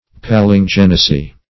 Palingenesis \Pal`in*gen"e*sis\, Palingenesy \Pal`in*gen"e*sy\,